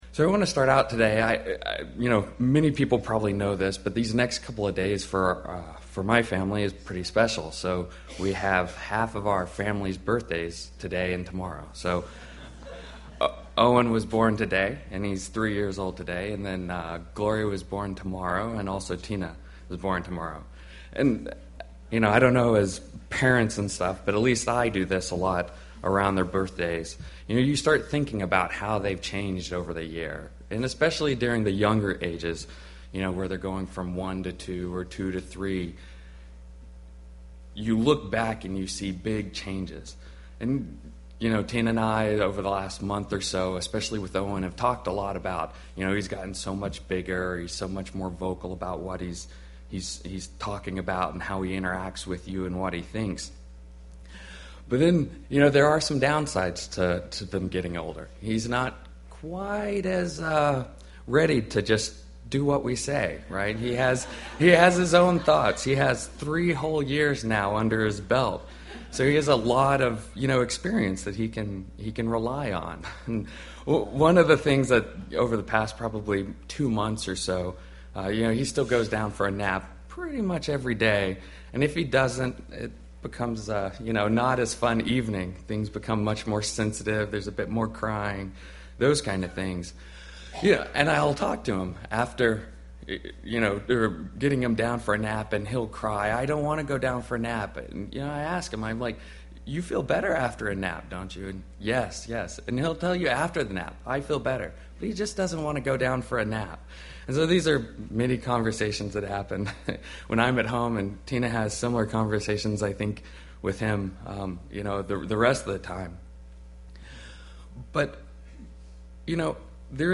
Given in Seattle, WA
Print UCG Sermon